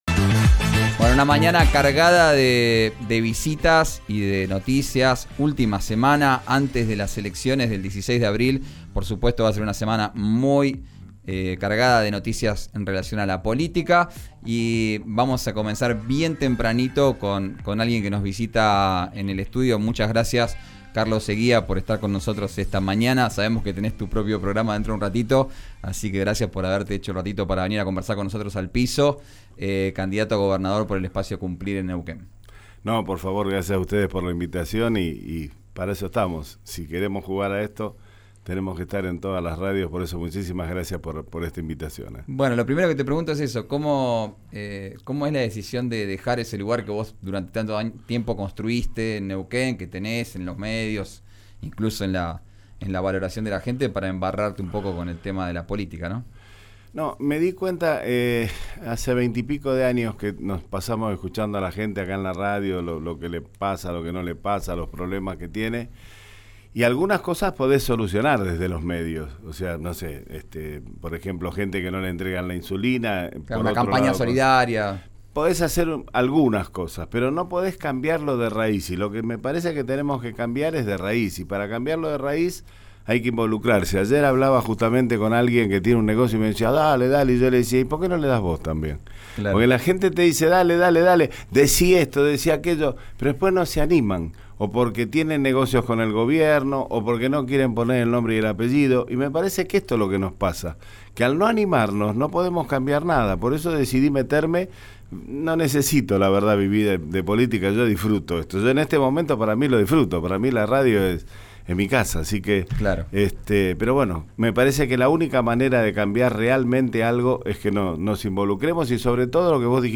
visitó el estudio de RÍO NEGRO RADIO. Repasó sus propuestas de cara a las elecciones del 16 de abril.